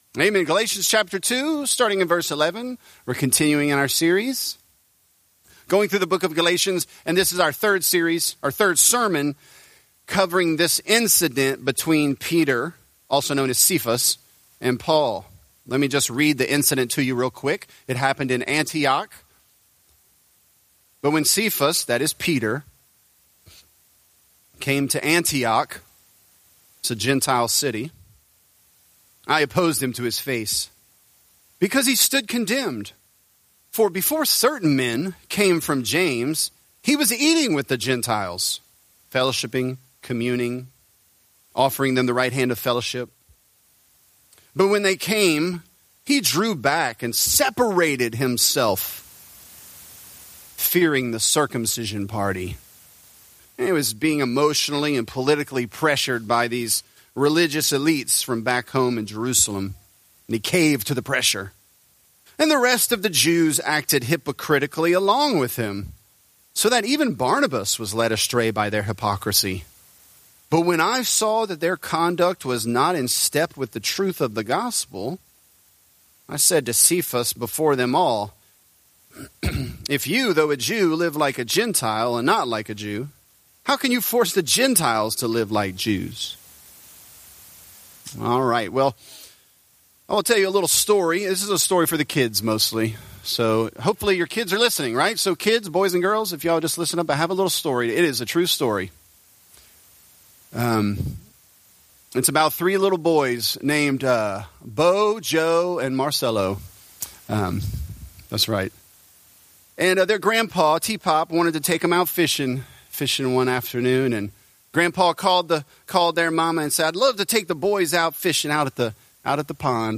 This is a part of our sermon series on the book of Galatians.